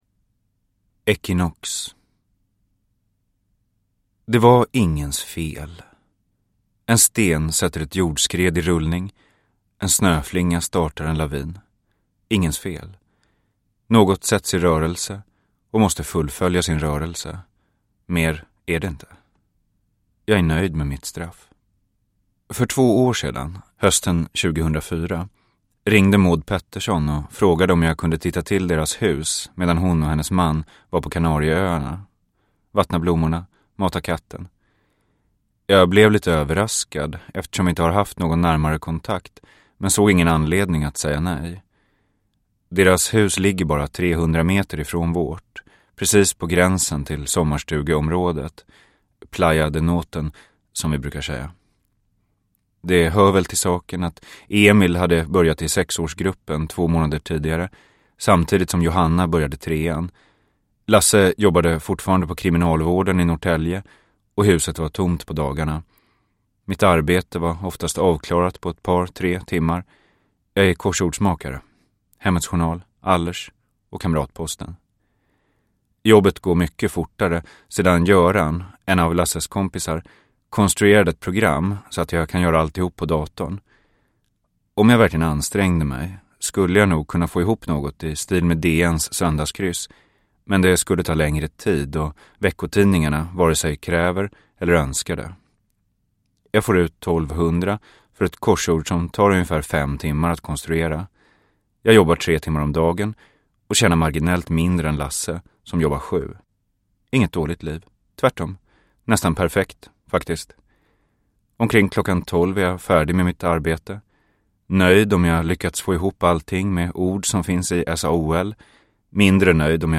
Equinox – Ljudbok – Laddas ner